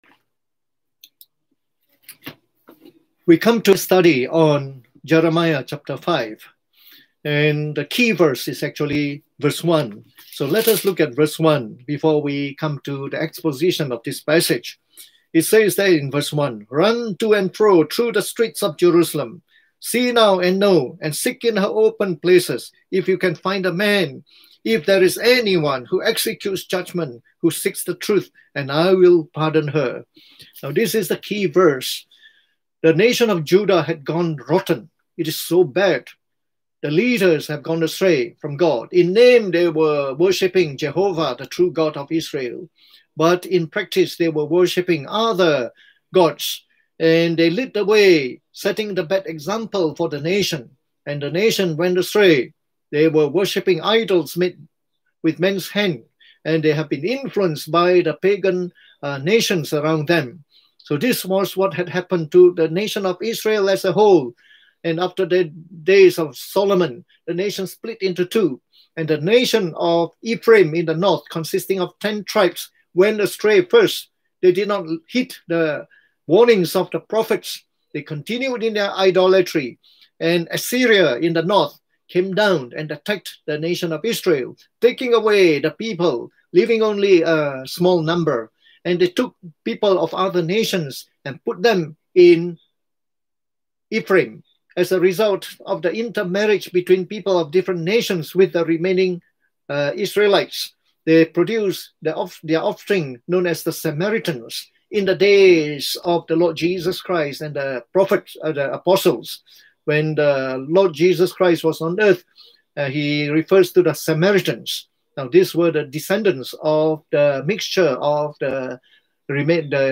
Message delivered online in the Morning Service on the book of Jeremiah